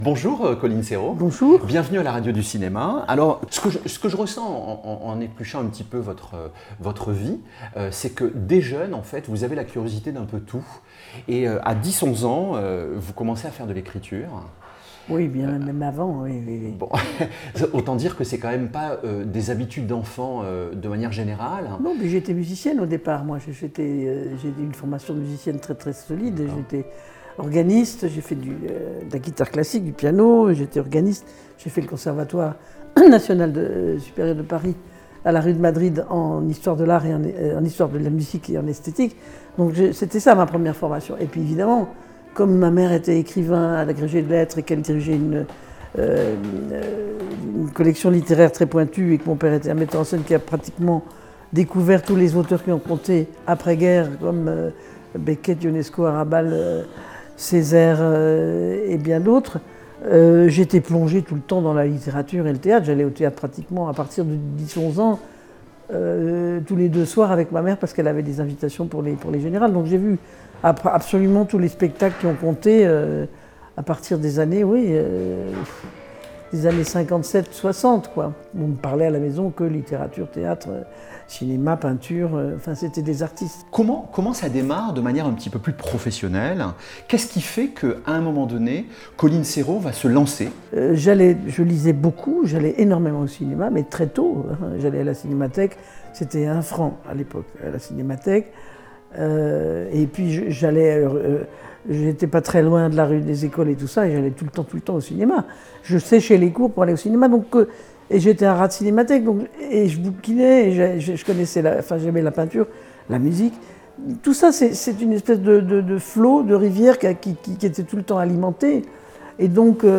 %%Les podcasts, interviews, critiques, chroniques de la RADIO DU CINEMA%%